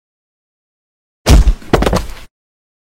Thud Sound Eff Bouton sonore